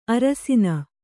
♪ arasiṇa